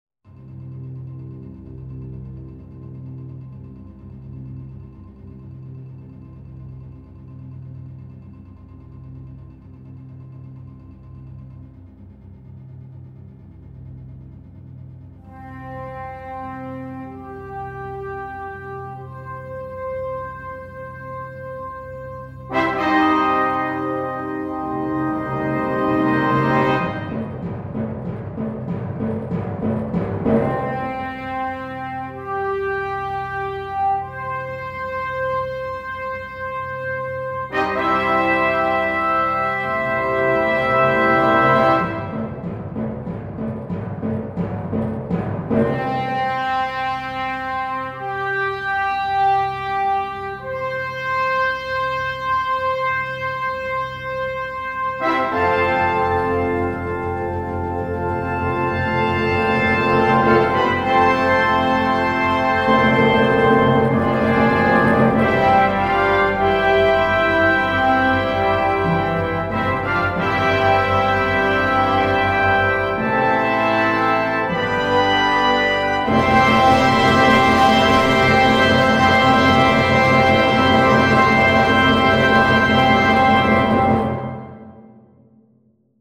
2. Brass Band
komplette Besetzung
ohne Soloinstrument
Eröffnungswerk
Fanfare